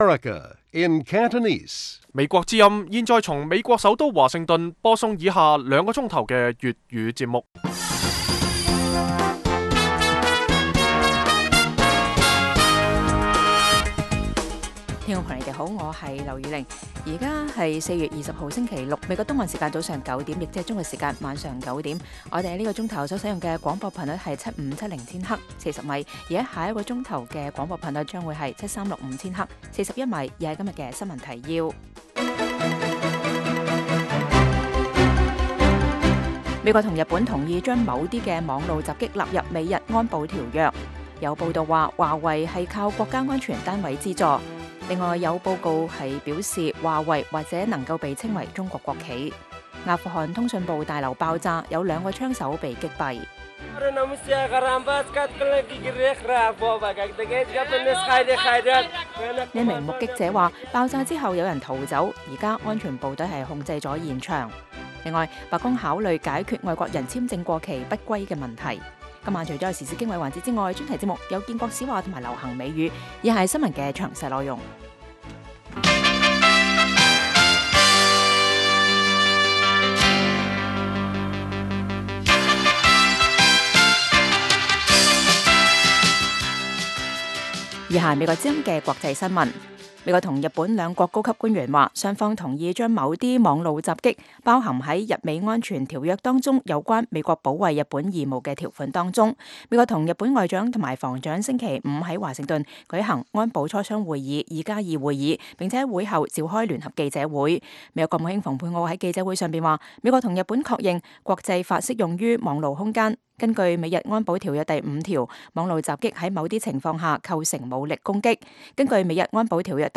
北京時間每晚9－10點 (1300-1400 UTC)粵語廣播節目。內容包括國際新聞、時事經緯和英語教學。